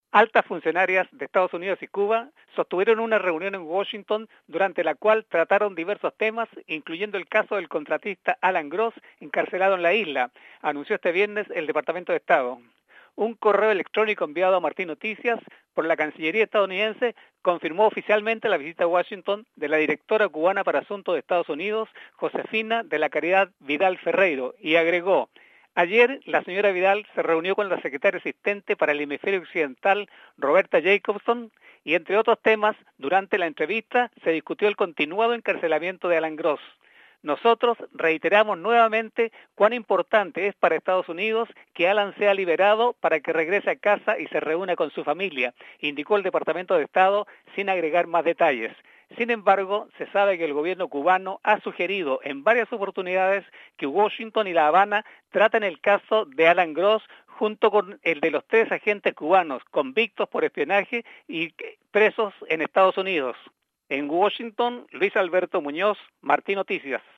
Conversaciones en Washington de altos funcionarios de Cuba y EEUU sobre Alan Gross. Repòrta desde la capital